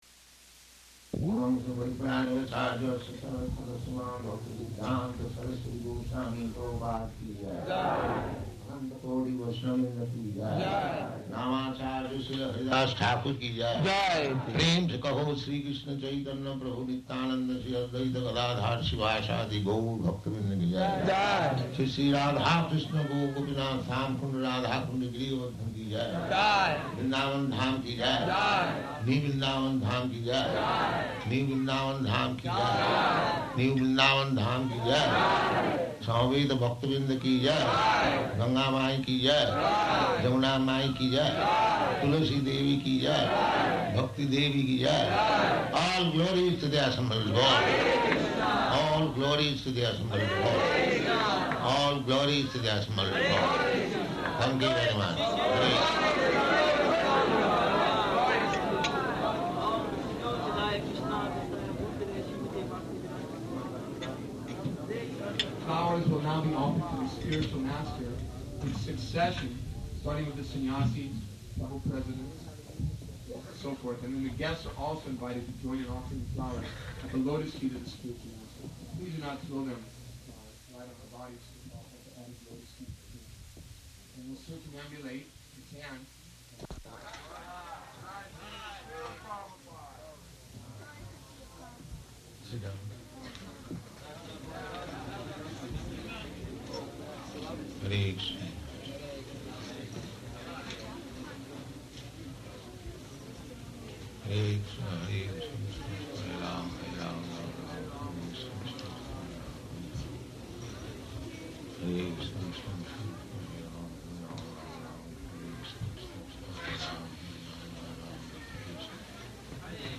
Śrī Vyāsa-pūjā Address --:-- --:-- Type: Appearance and Disappearance days Dated: September 2nd 1972 Location: New Vrindavan Audio file: 720902VP.NV.mp3 Prabhupāda: [ prema-dhvani ] Thank you very much.